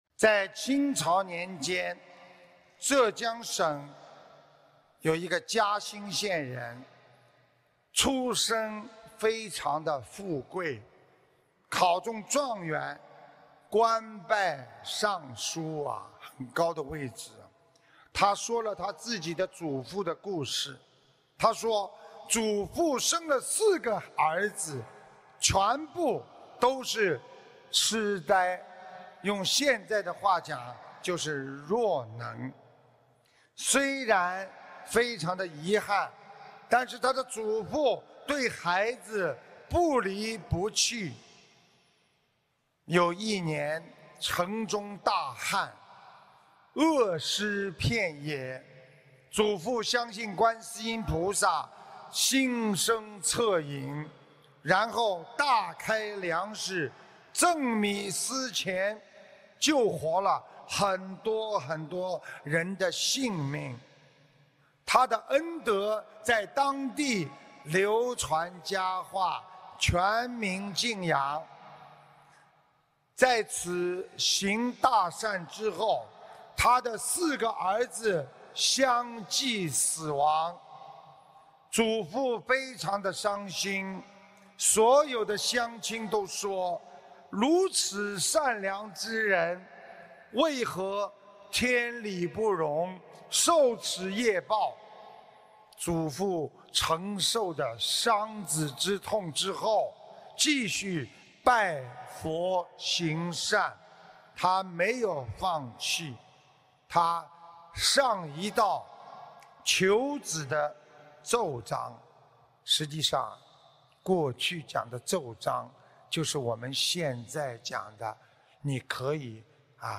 音频：嘉兴县人的善恶因缘!2016年7月1日 香港法会现场开示